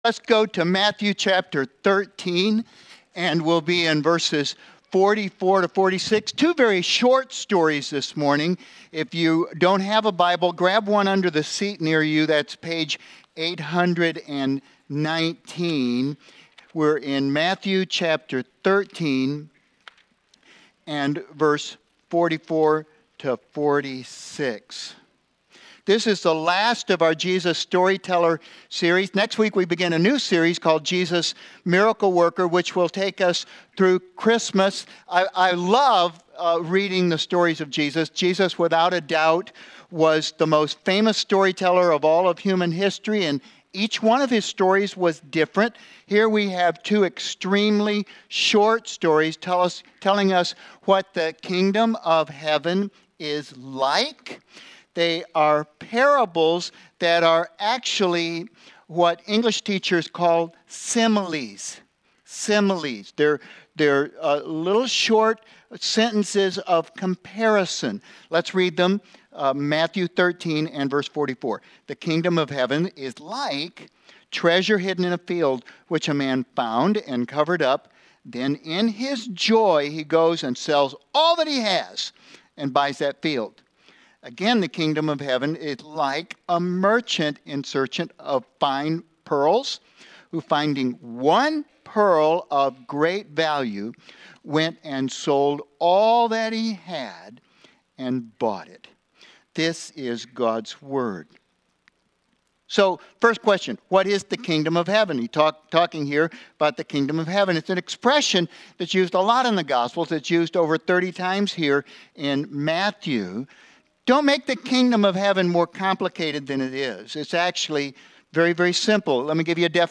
Jesus-Story-Teller Passage: Matthew 13:44-46 Service Type: Sunday Morning « Jesus